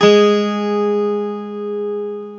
Index of /90_sSampleCDs/Club-50 - Foundations Roland/GTR_xAc 12 Str/GTR_xAc 12 Str 1
GTR X12 ST07.wav